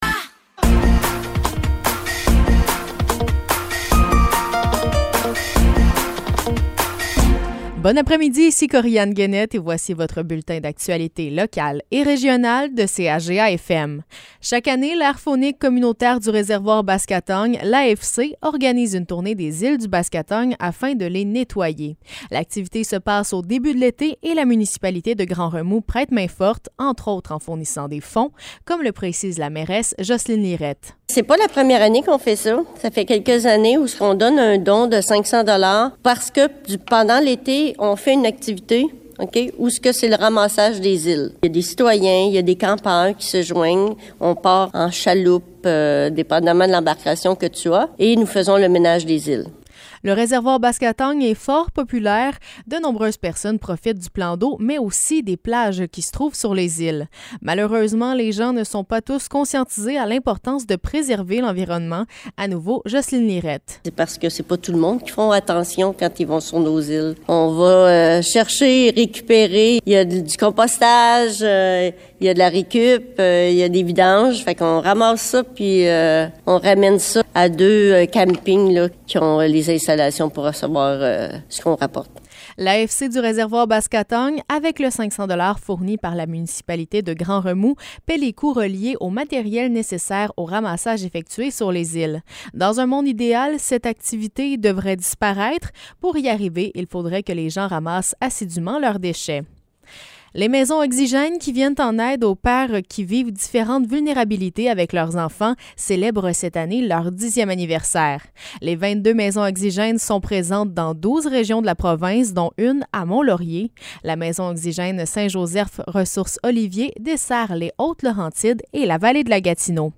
Nouvelles locales - 9 mars 2023 - 15 h